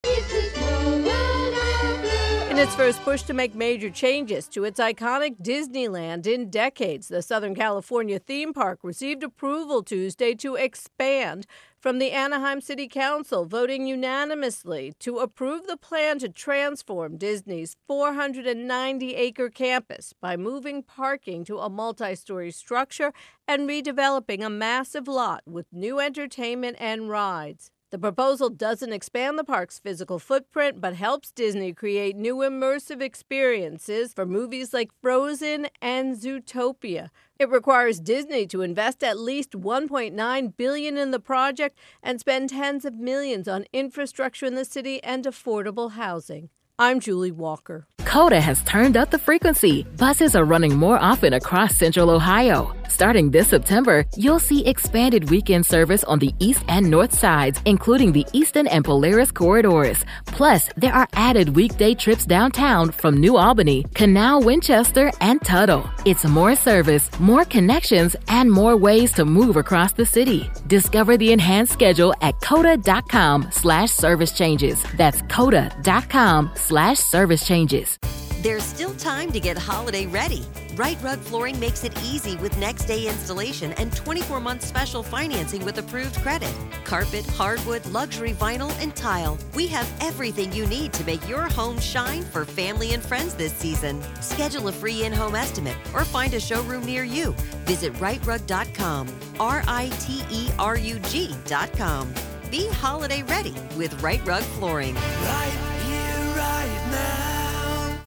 ((wrap begins with music))